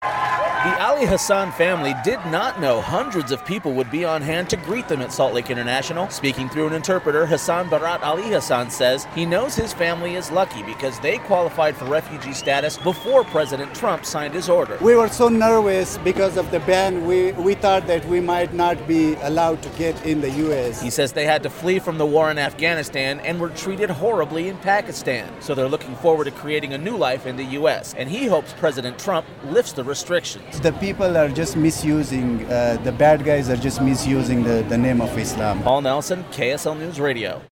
"Last" refugee family arrives to cheering crowd at Salt Lake International